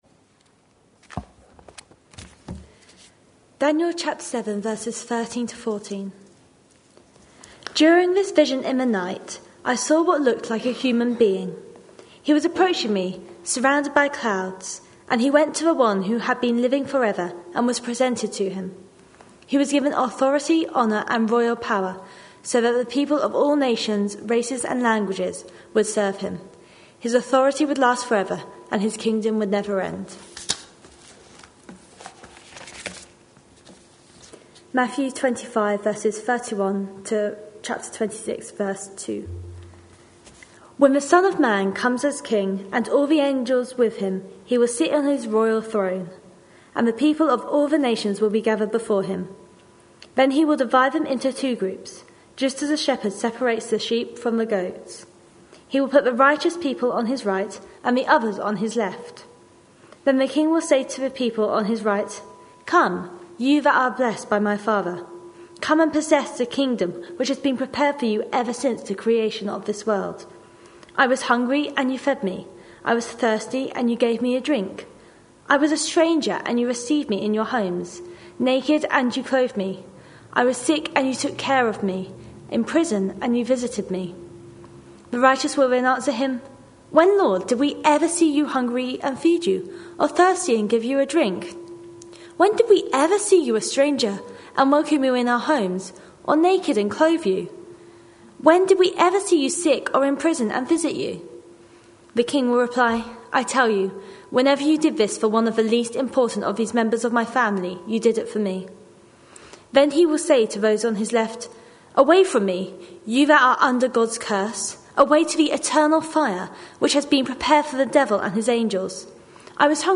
A sermon preached on 26th January, 2014, as part of our Stories with Intent series.